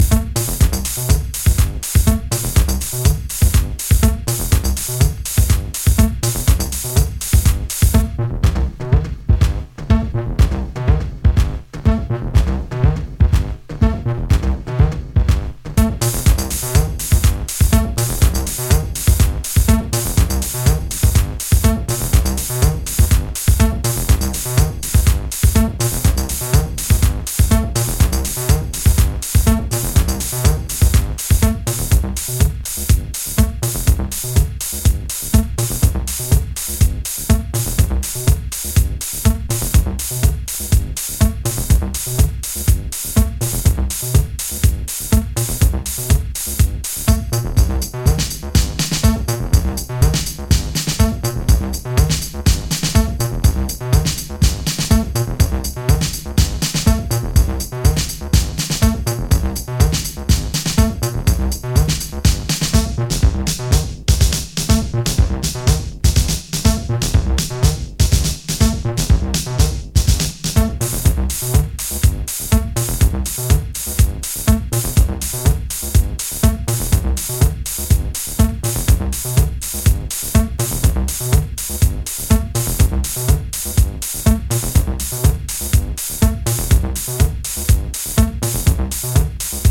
4 raw stripped down Chicago acid cuts